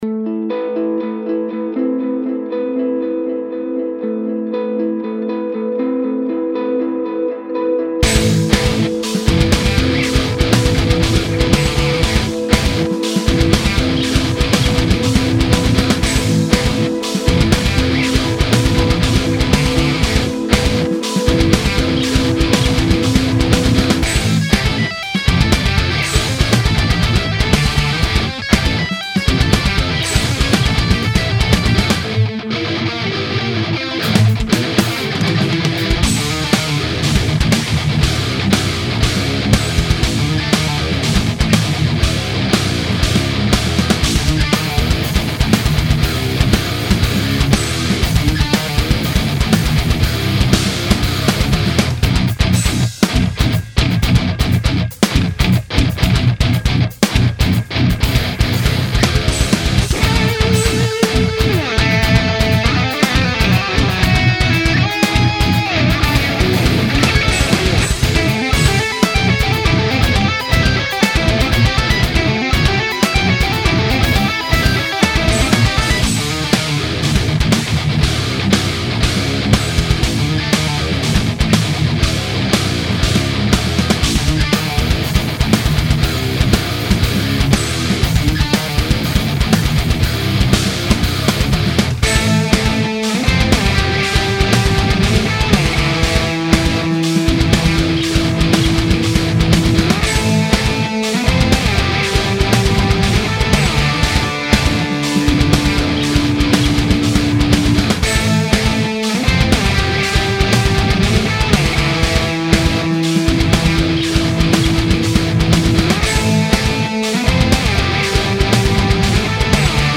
19:09:40 » Отличная техничная тема !